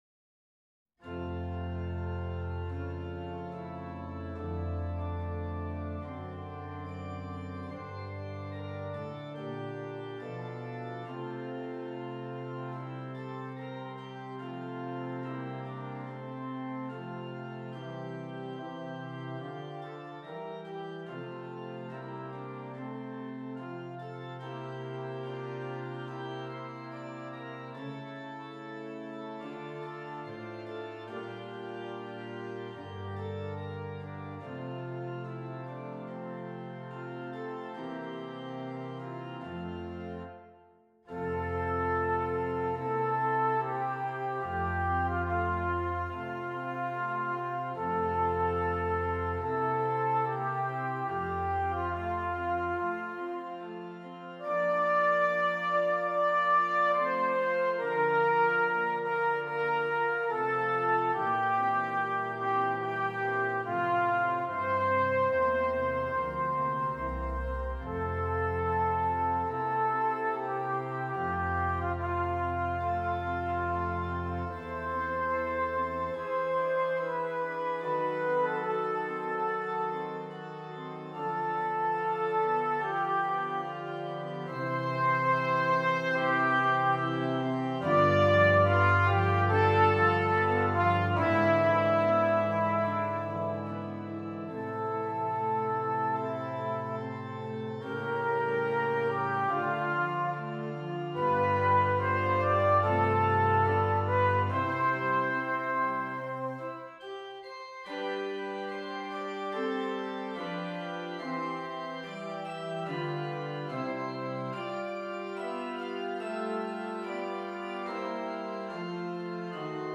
Trumpet and Keyboard